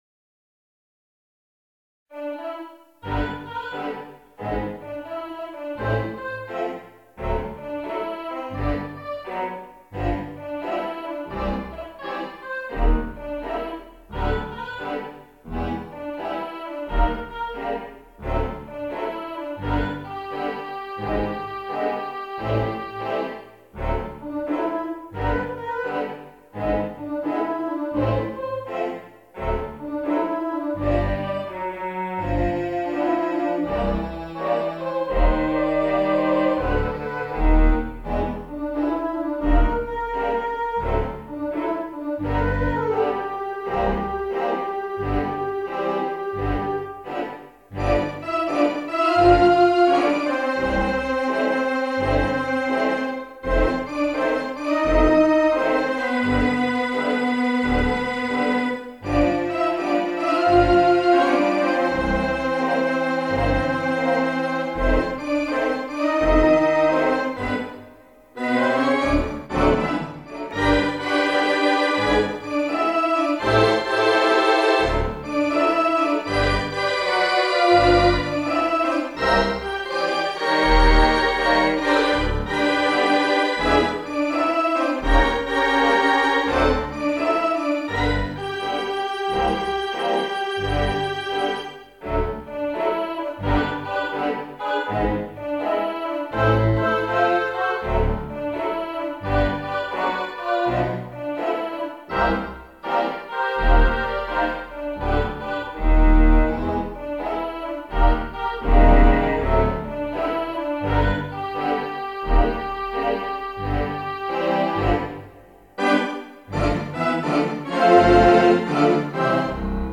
Virtual Theatre Pipe Organ